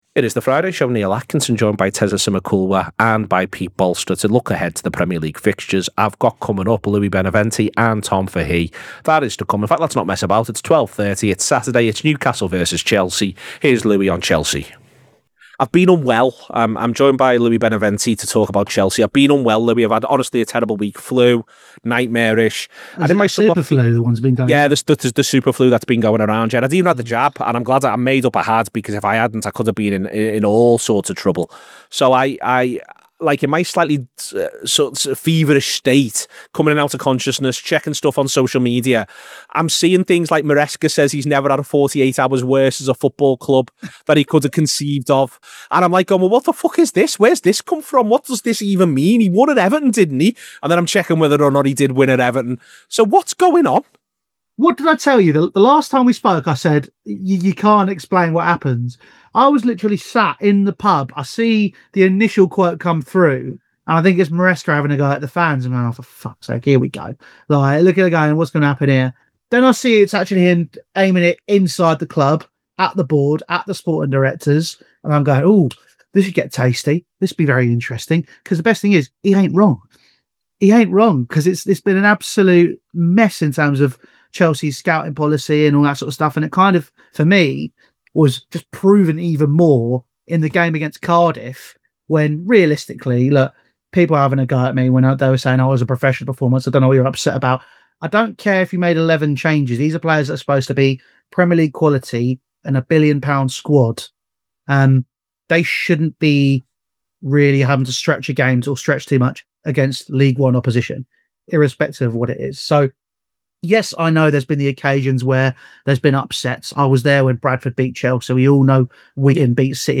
Premier League fans look ahead to the weekend’s games, including Newcastle’s early Saturday kick off against Chelsea and Liverpool’s trip to Tottenham.